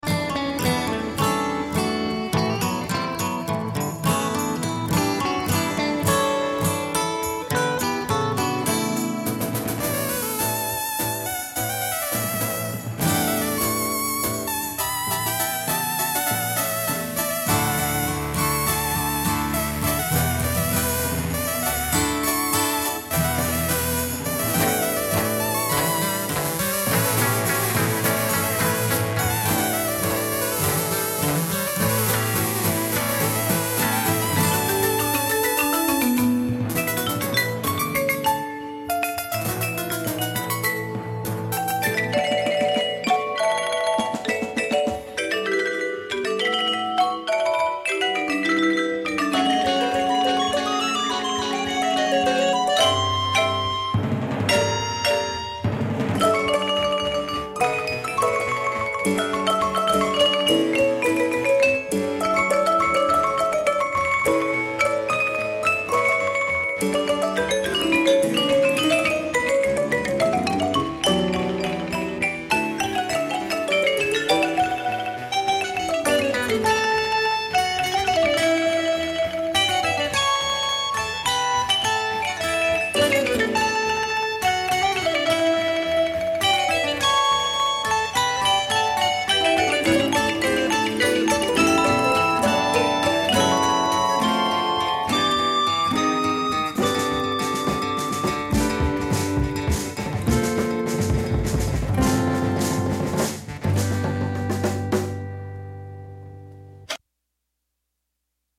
Today’s explorations will lean toward jazz.